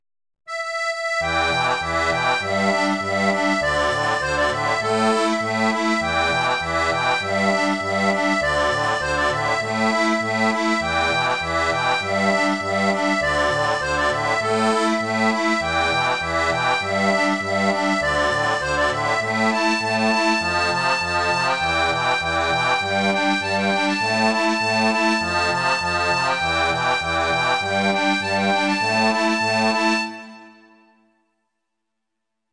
Version revisitée par mes soins
Chanson française